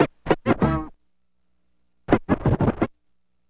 HORN SCRA07L.wav